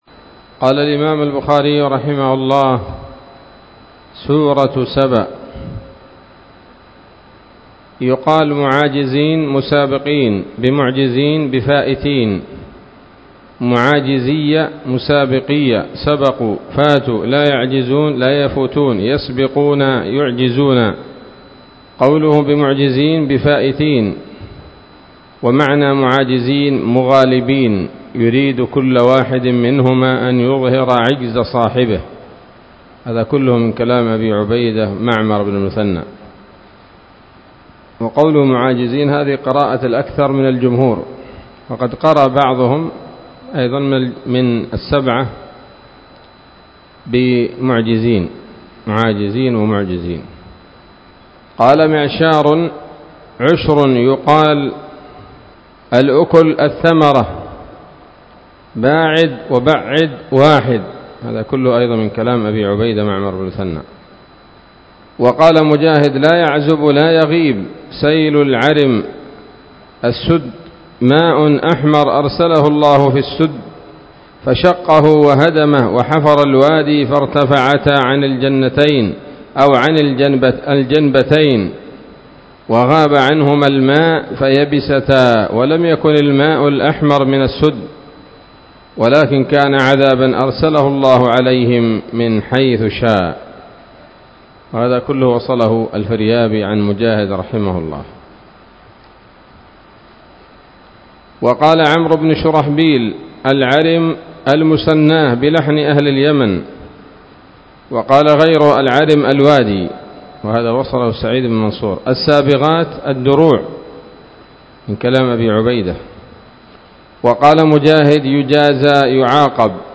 الدرس العاشر بعد المائتين من كتاب التفسير من صحيح الإمام البخاري